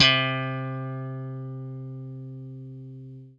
FENDRPLUCKAD.wav